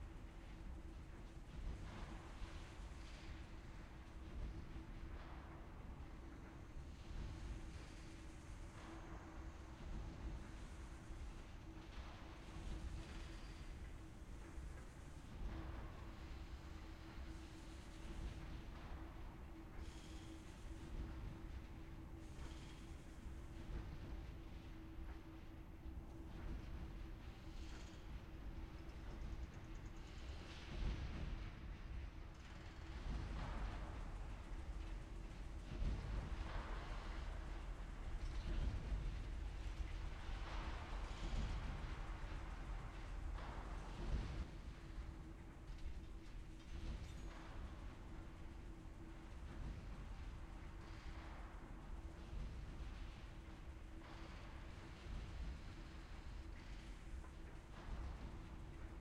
sfx-ambience-loop-bridgeofprogress.ogg